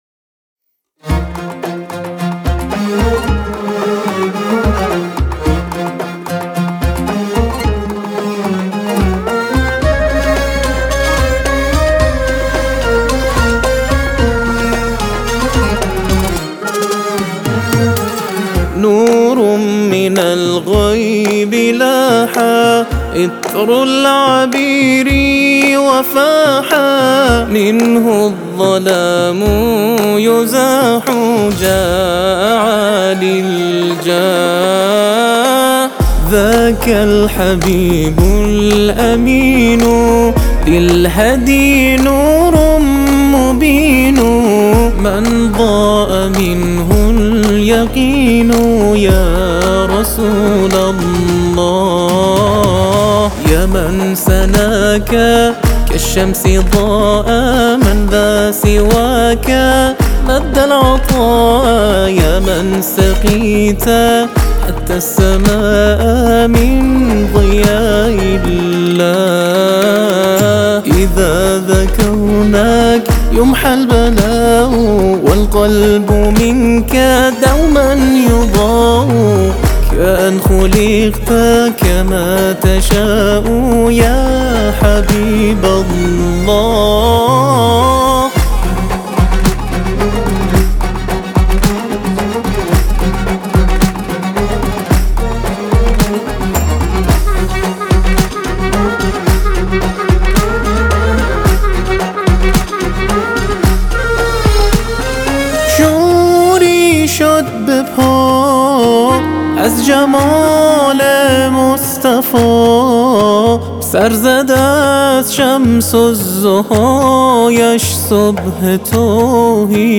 اثر آوایی